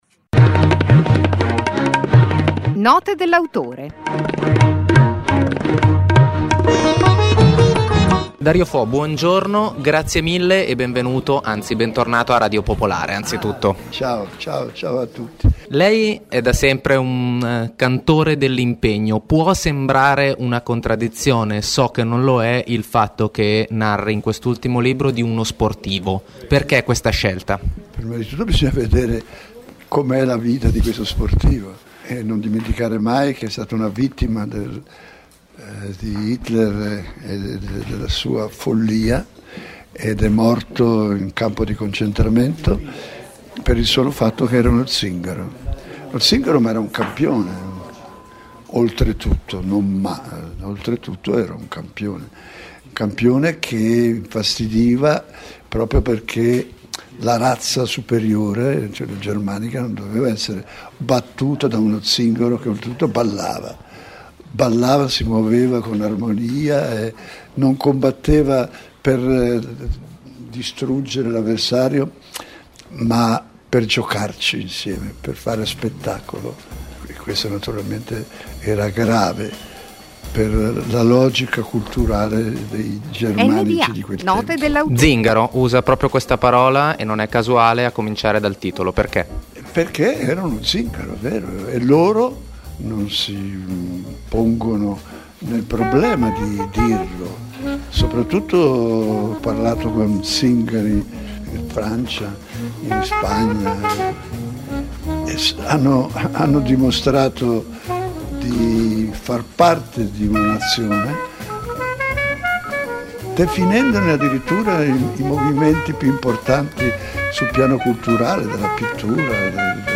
Un appuntamento quasi quotidiano, sintetico e significativo con un autore, al microfono delle voci di Radio Popolare. Note dell’autore è letteratura, saggistica, poesia, drammaturgia e molto altro.